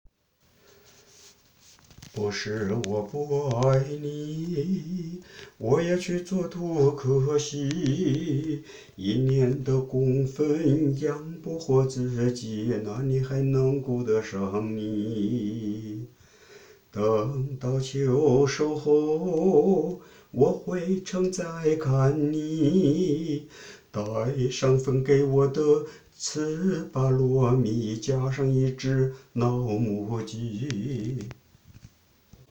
王维倩也可以唱这么调皮的歌
王维倩是上海歌剧院的首席女中音，高雅艺术的代表，在电视娱乐节目中也会很调皮的唱唱小调，很有趣也很有意思，唱的是70、80年代有些男女朋友在谈婚论嫁的事情。